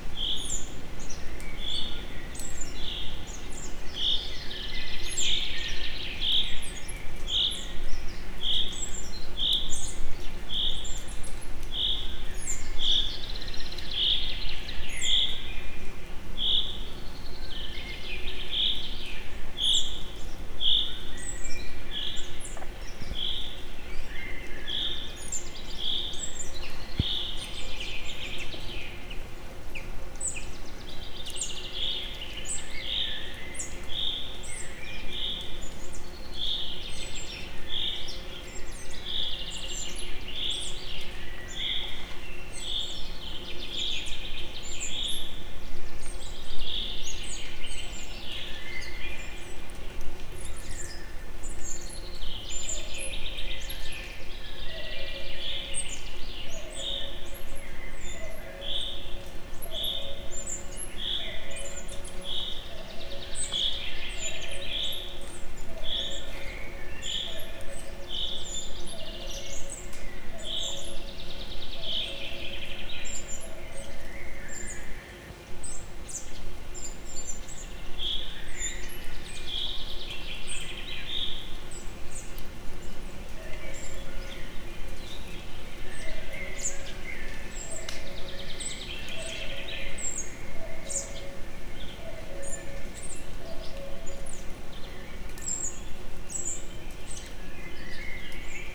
erdeipinty_himrevirhangja_egerturistahaz01.38.WAV